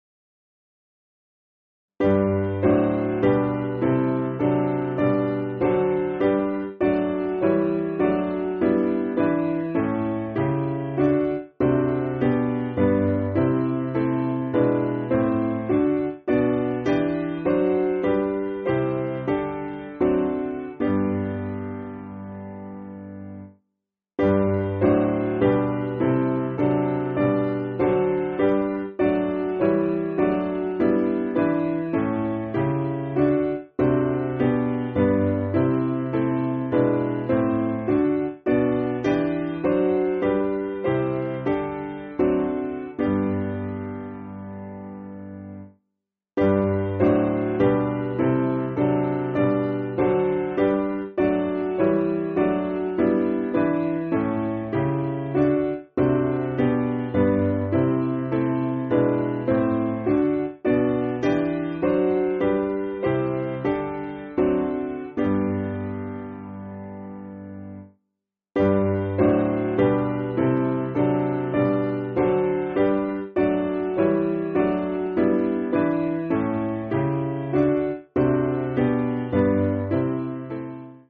Simple Piano
(CM)   4/G